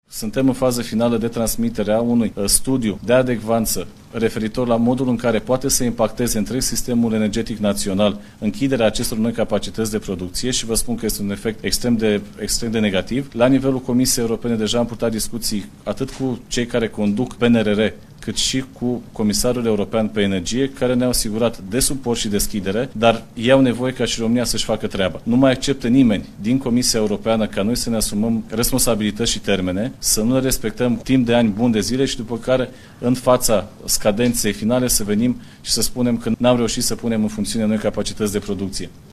Bogdan Ivan, ministrul Energiei: „La nivelul Comisiei Europene deja am purtat discuții atât cu cei care conduc PNR, cât și cu comisarul european pe energie”